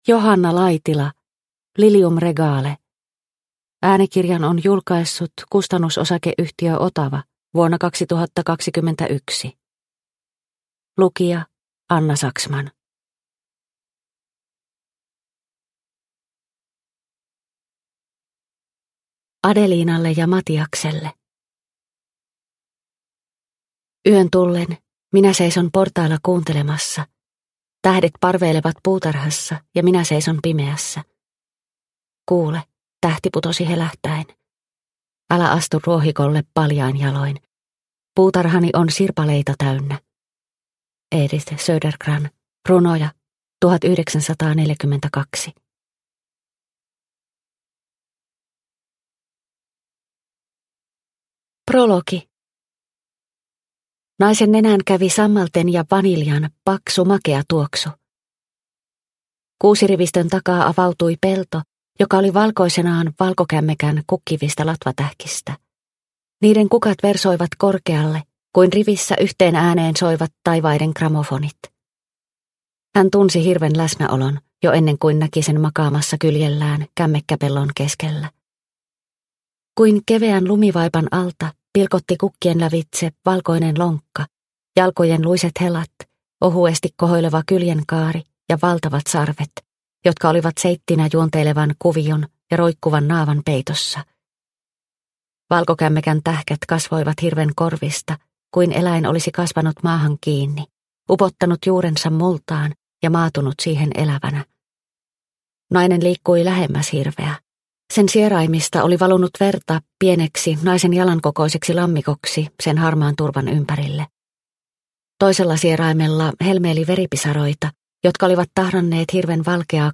Lilium regale mp3 – Ljudbok – Laddas ner